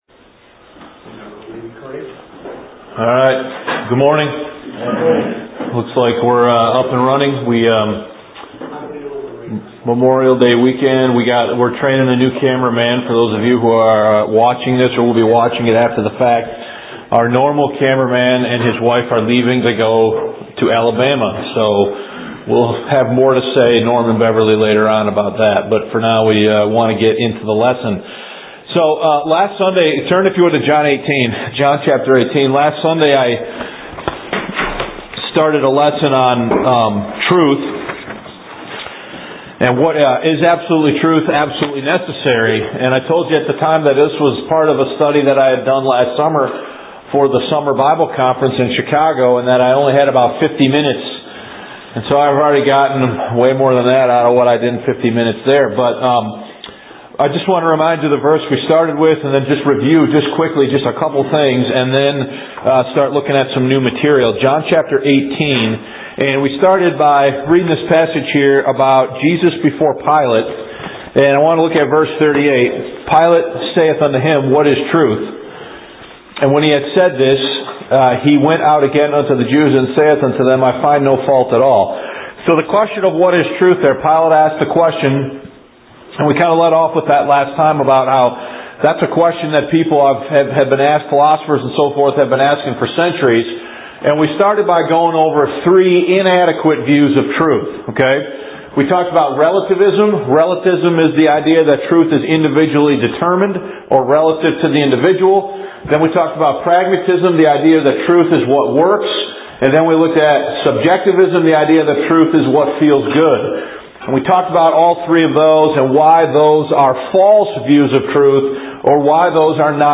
Our booklet Truth vs Tolerance was used as a resource to prepare this lesson.